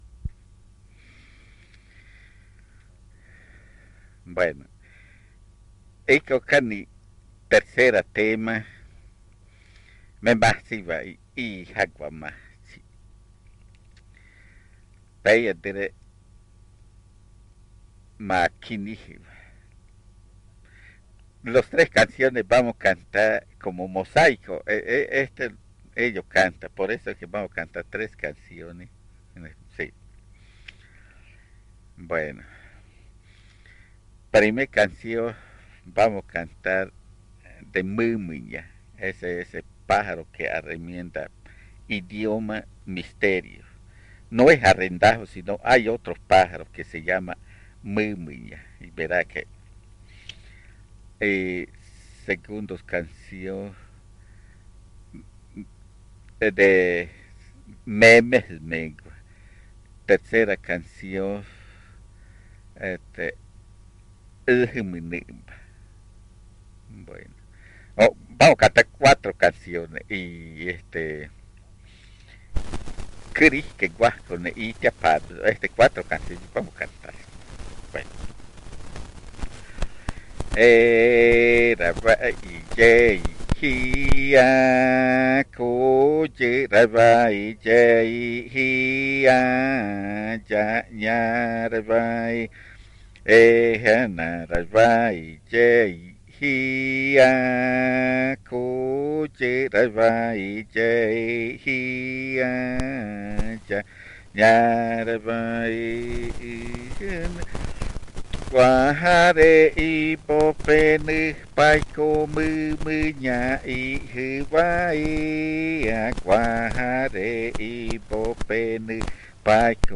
El audio incluye los lados A y B del casete.